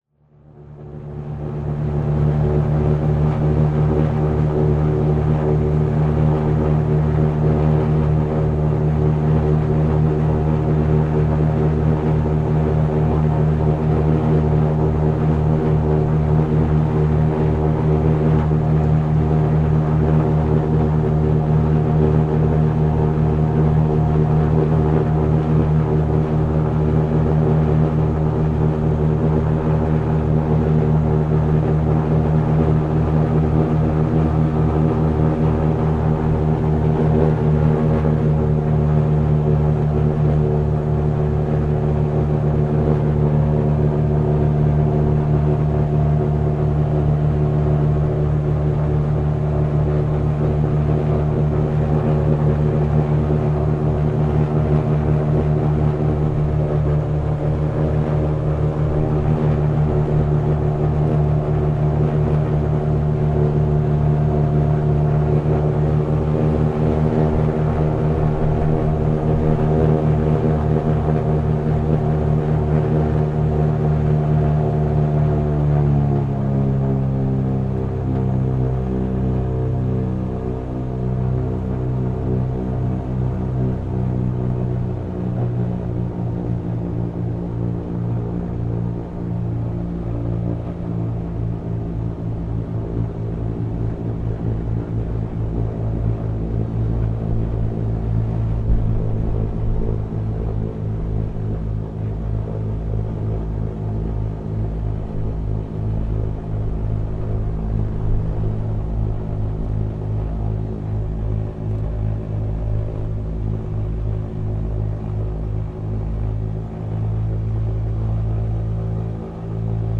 Звуковая последовательность: запуск двигателя, прогрев, разгон, остановка мотора